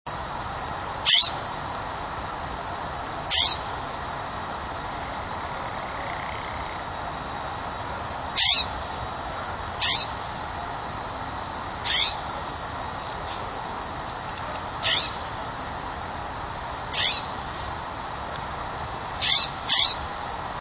Western Scrub Jay
Comments: Trill is monotone trill which increases and then decreases in volume. Sounds like a very fast ovenbird that is trying not to be so loud.  This is a quiet, timid bird with a soft voice even up close.
Stokes: Call is a musical trill similar to Chipping Sparrow's, but more musical.  Call is a sweet "chip".
Peterson: "Song a trill, like that of Chipping Sparrow but looser, more musical, and slower in tempo."
jay_scrub_788.wav